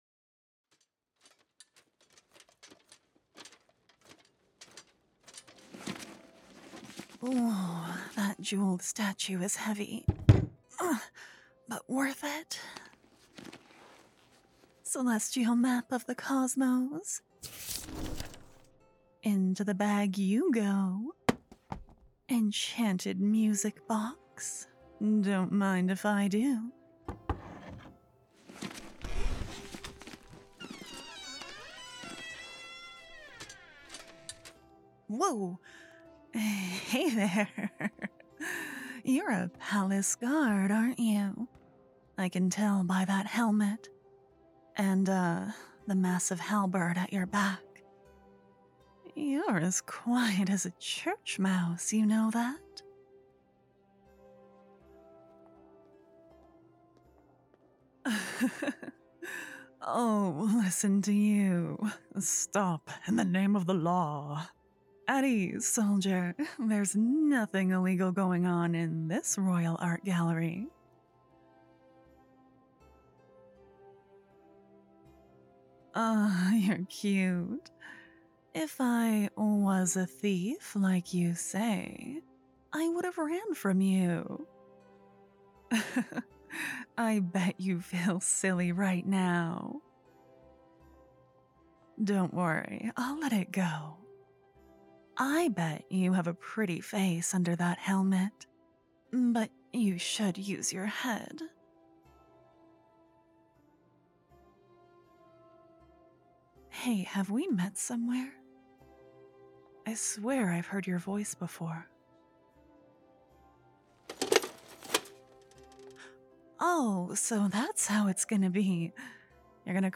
Warnings with spoilers: On screen death of an unnamed character with a small verbalization. Poisoned listener character but they're lovingly attended to.
When you write, a common piece of advice is to keep your characters moving, but for audio roleplays that gets difficult~!